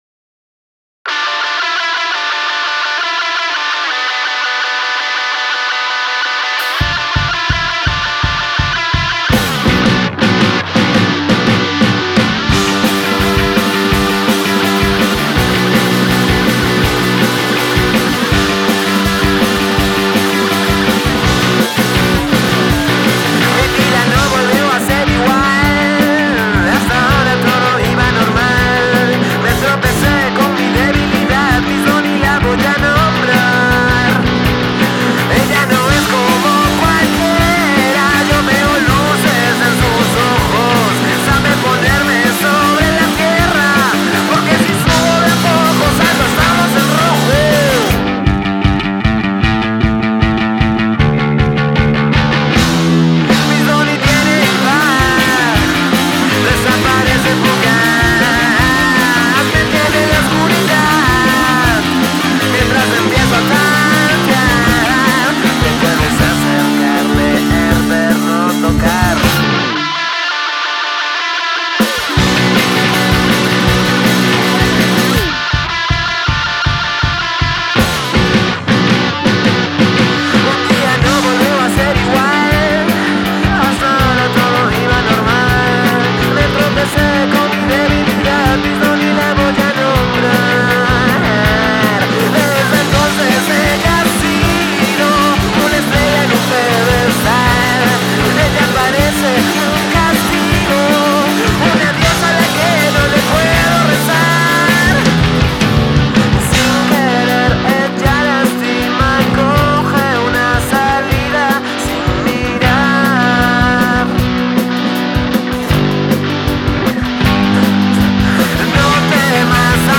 rocanrol en su estado más puro.
Rock and roll